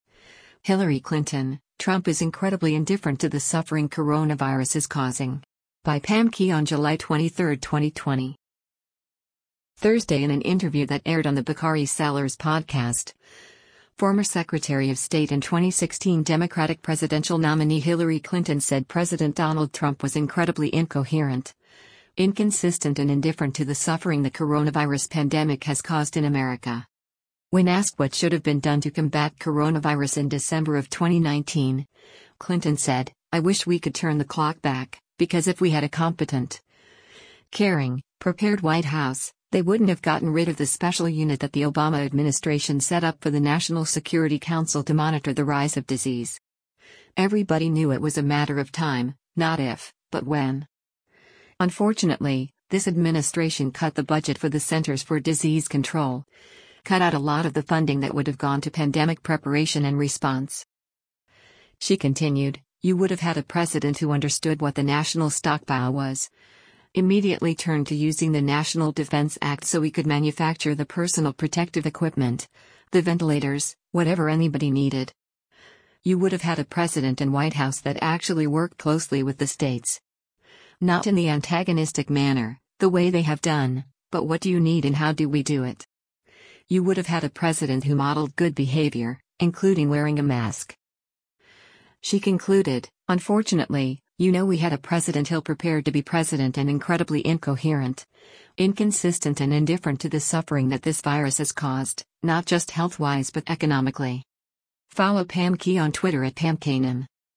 Thursday in an interview that aired on “The Bakari Sellers Podcast,” former Secretary of State and 2016 Democratic presidential nominee Hillary Clinton said President Donald Trump was “incredibly incoherent, inconsistent and indifferent to the suffering” the coronavirus pandemic has caused in America.